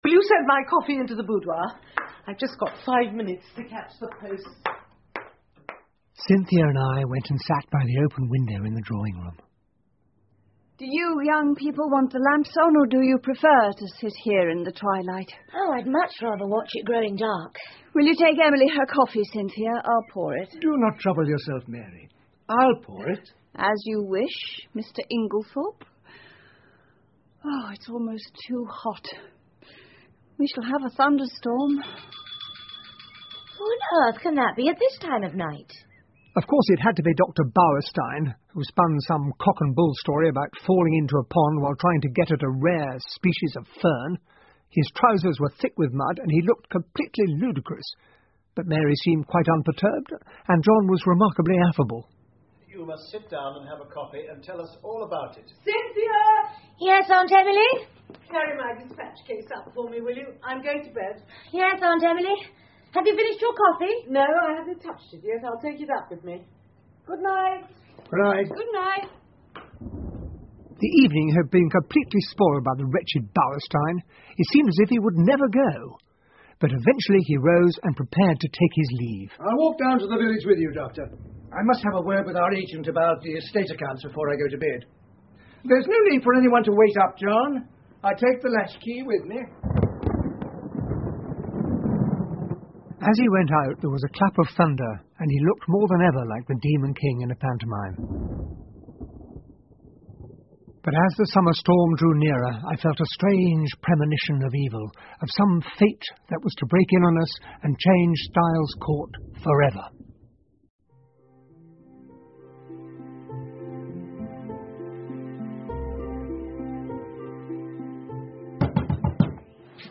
英文广播剧在线听 Agatha Christie - Mysterious Affair at Styles 6 听力文件下载—在线英语听力室